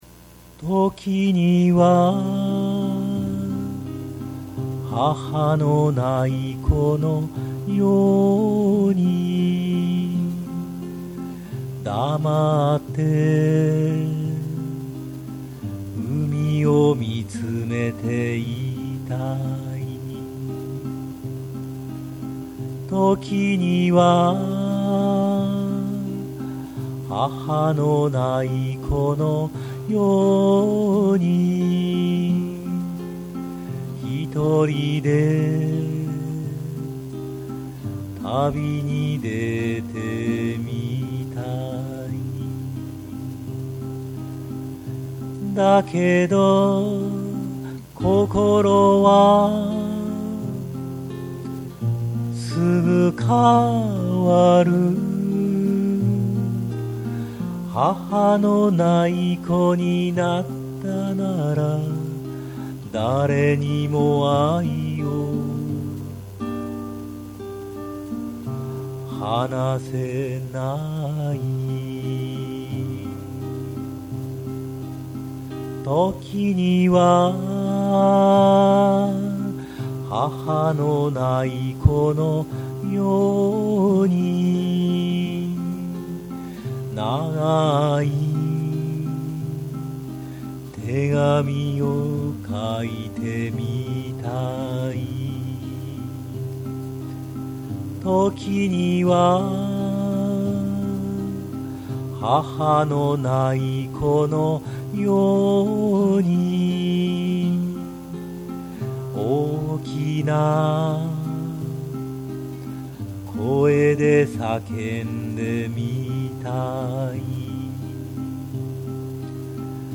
２００６．３．６アップ　　これは数年前の声です。多重録音ではなく、キーも下げているので単調ですが、、、